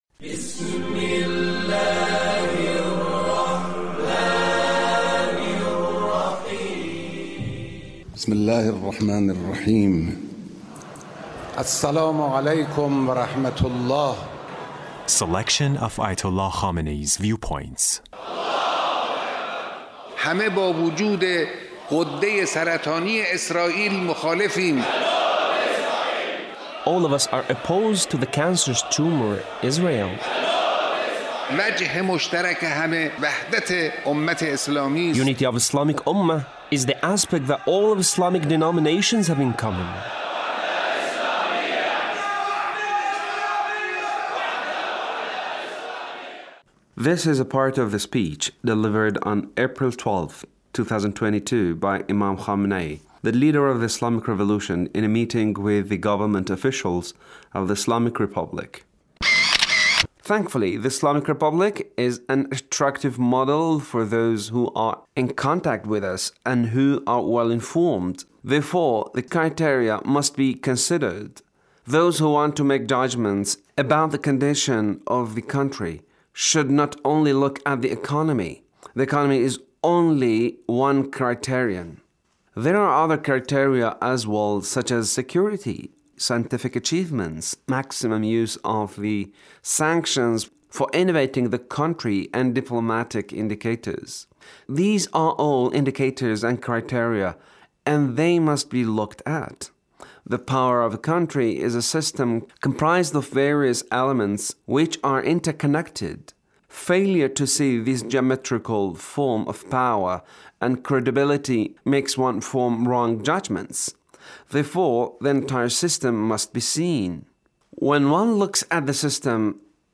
The Leader's speech in a meeting with Government Officials